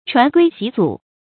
傳圭襲組 注音： ㄔㄨㄢˊ ㄍㄨㄟ ㄒㄧˊ ㄗㄨˇ 讀音讀法： 意思解釋： 謂取得功名。